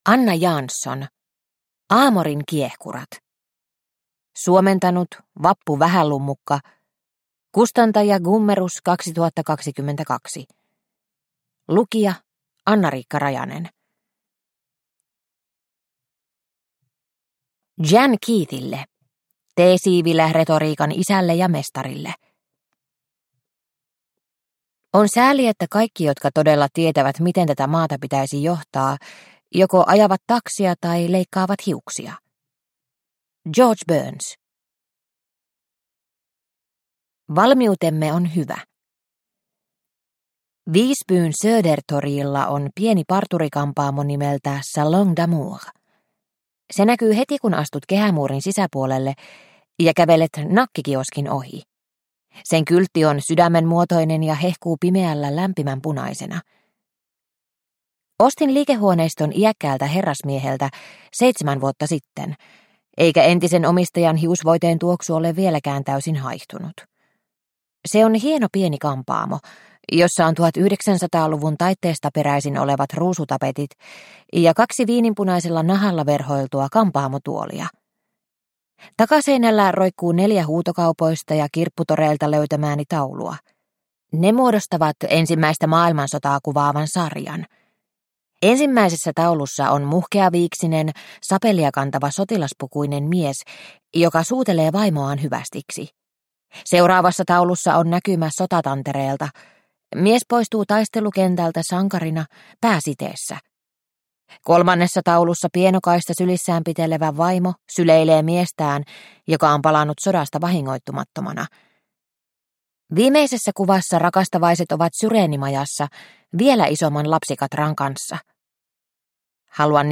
Amorin kiehkurat – Ljudbok – Laddas ner